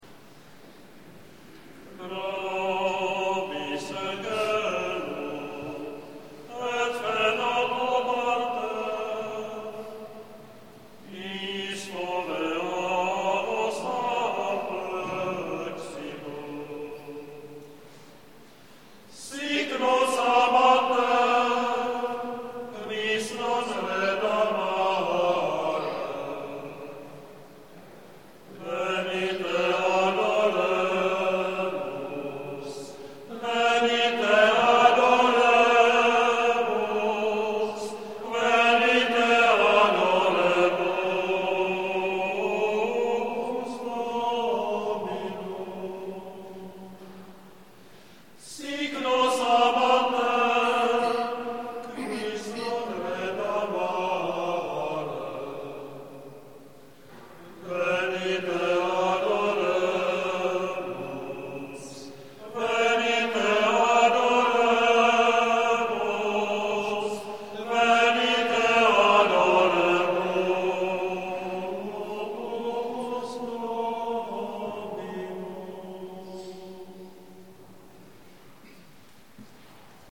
Gregoriánský chorál